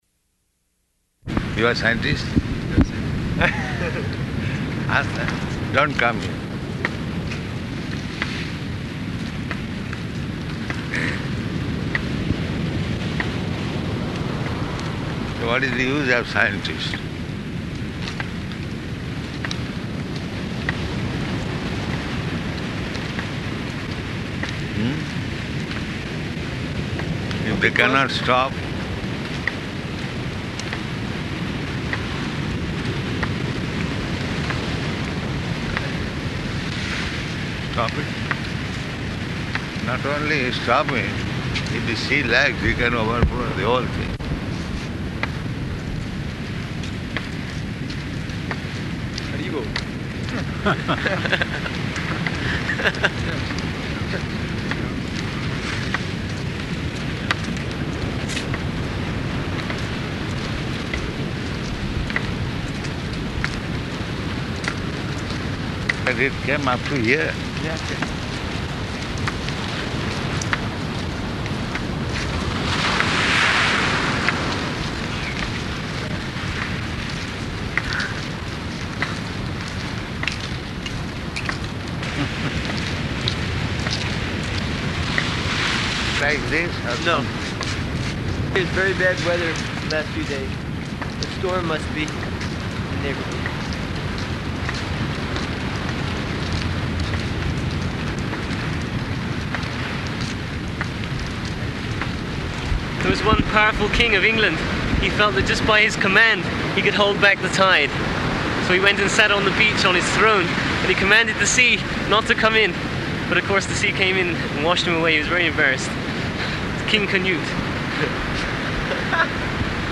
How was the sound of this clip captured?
Location: Durban